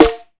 SNARE.102.NEPT.wav